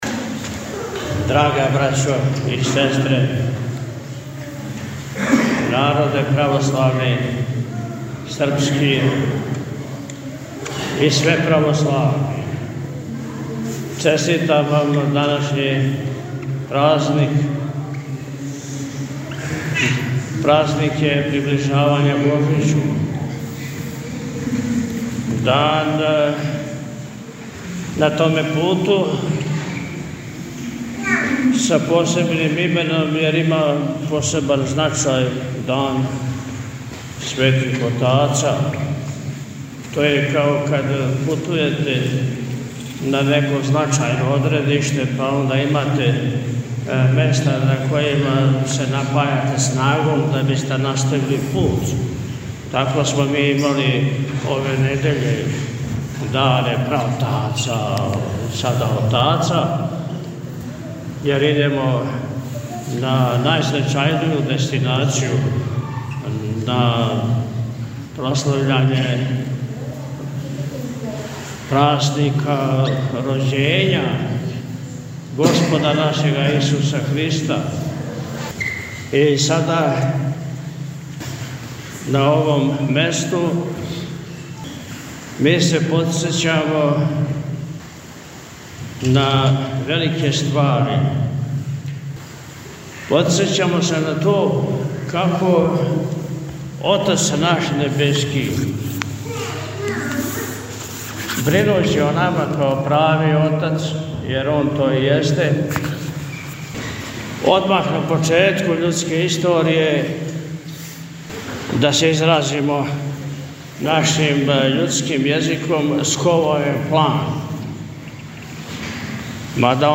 Guke-Oci-Beseda.mp3